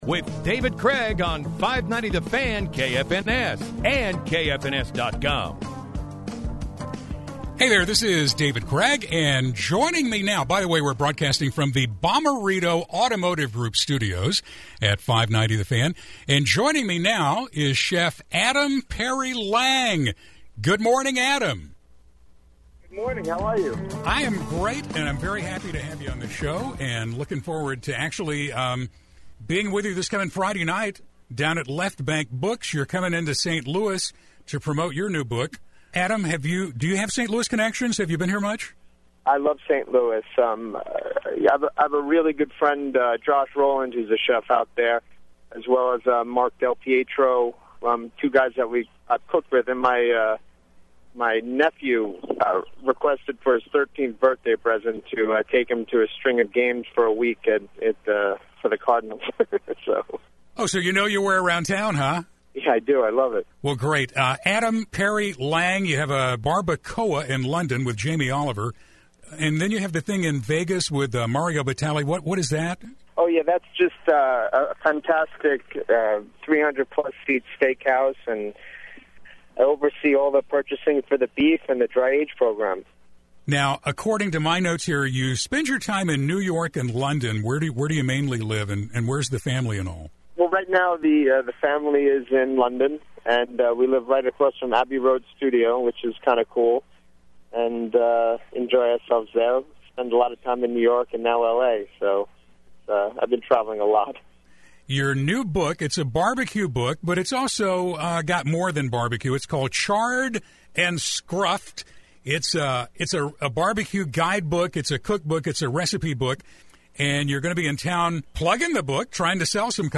Adam Perry Lang interview